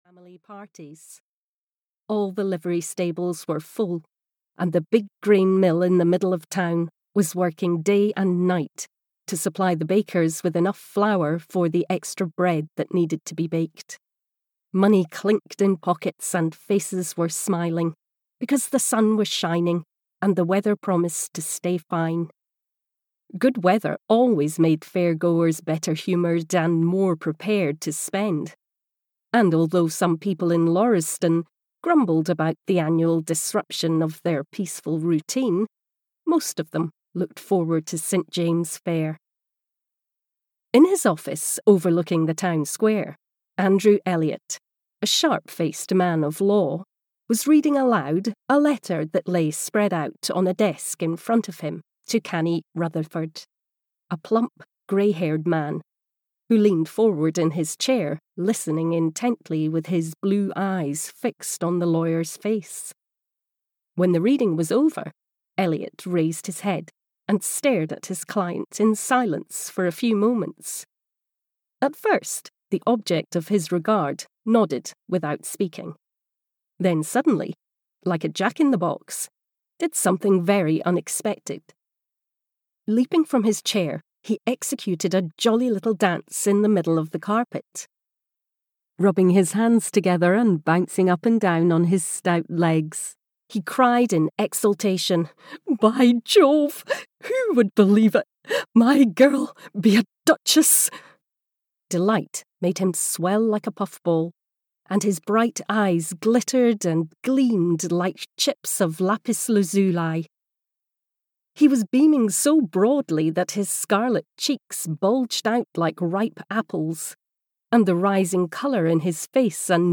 St James' Fair (EN) audiokniha
Ukázka z knihy